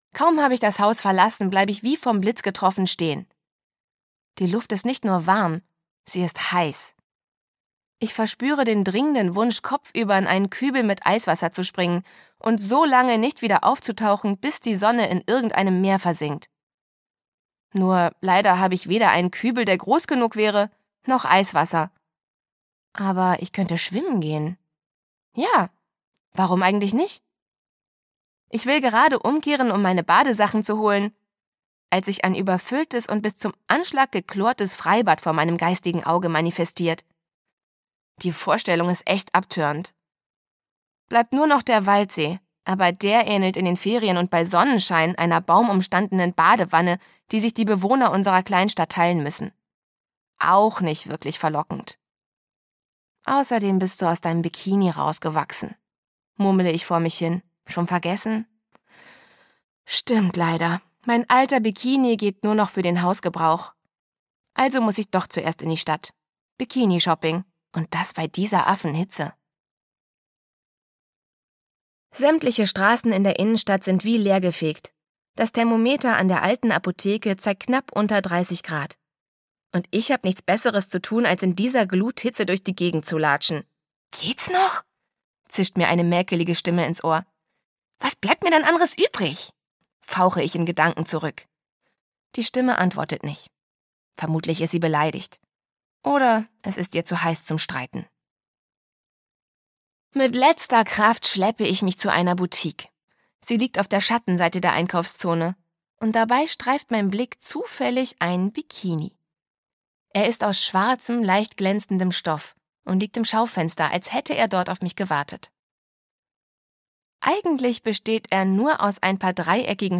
Conni 15 2: Mein Sommer fast ohne Jungs - Dagmar Hoßfeld - Hörbuch